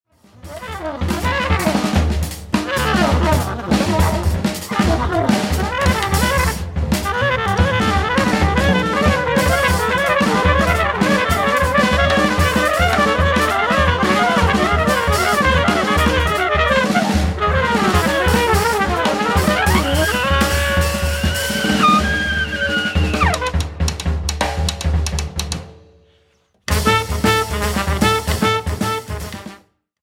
cornet, flugelhorn
clarinet, bass clarinet, tenor saxophone
drums
double bass
at Fattoria Musica Studios, Osnabrück, Germany
Das ist zeitgenössischer Jazz wie er Spass macht.
Dazu auch noch sehr gut aufgenommen.
The music is playful, buoyant, and knotty.